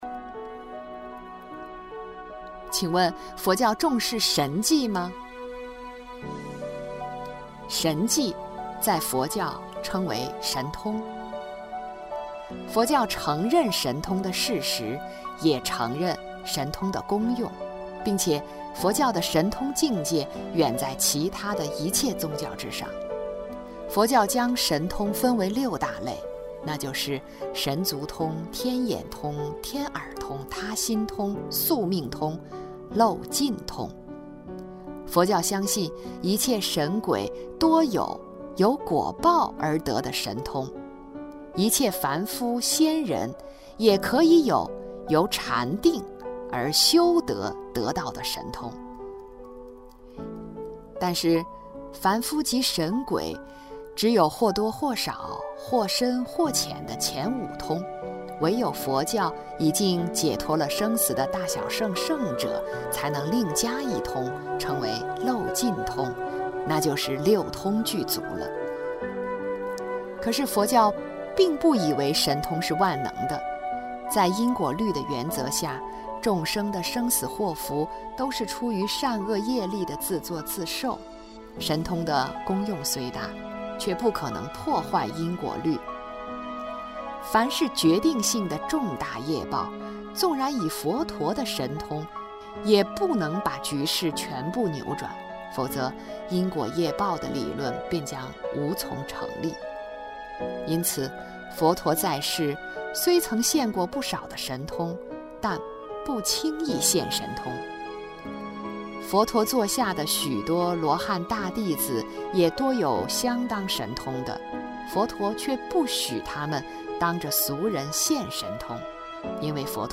网上柏林 > 问禅寮 > 有声书库 > 圣严法师《正信的佛教》 > 29佛教重视神迹吗？